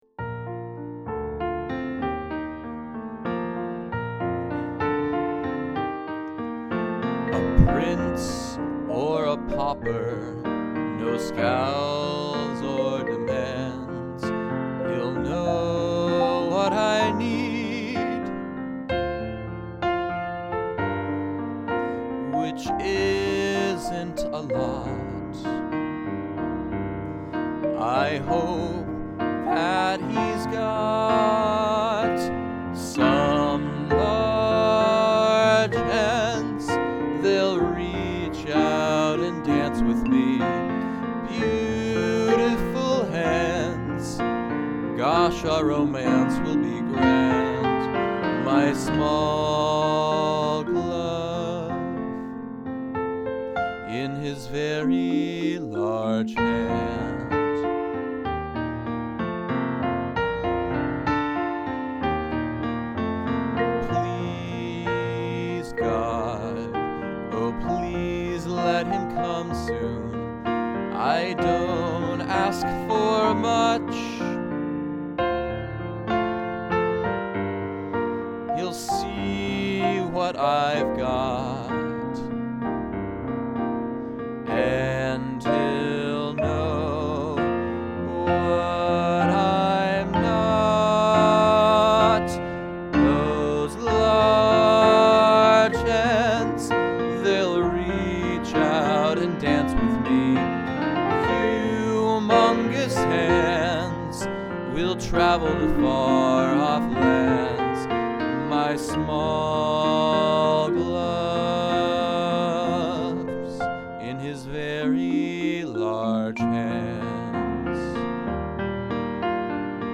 a new musical
Song Sample: "Large Hands"